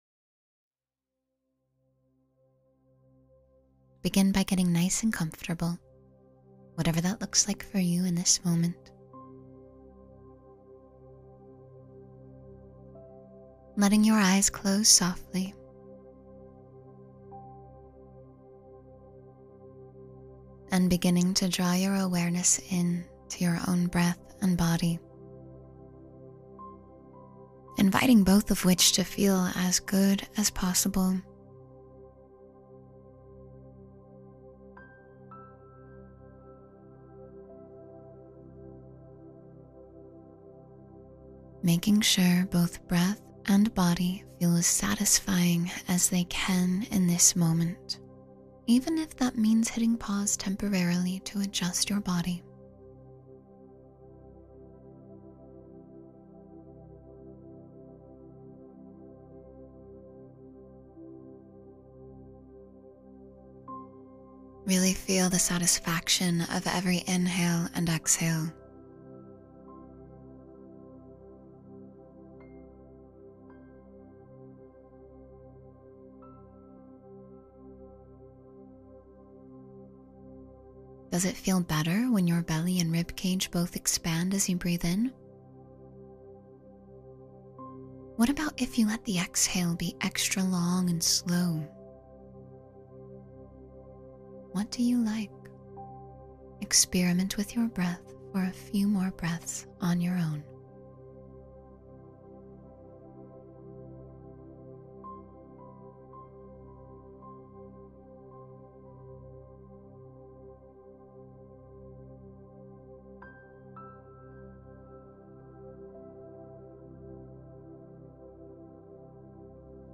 Receive All Life Has to Offer Now — Meditation for Openness and Abundance